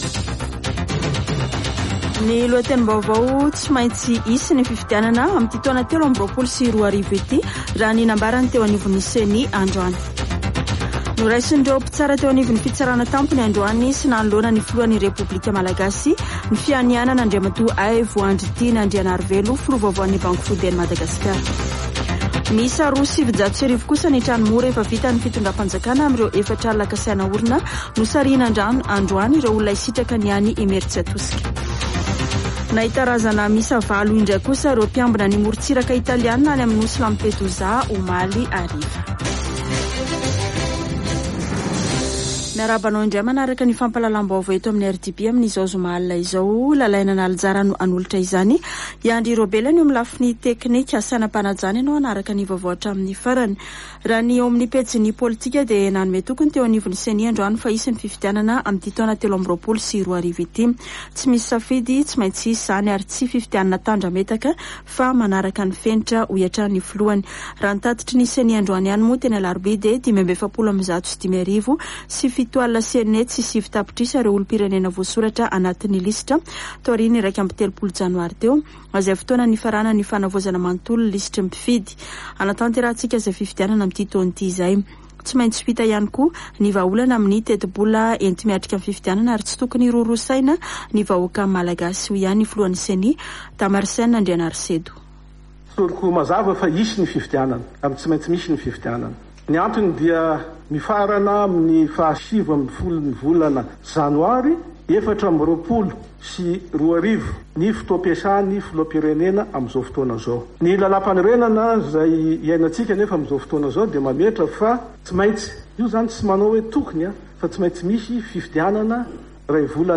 [Vaovao hariva] Zoma 3 febroary 2023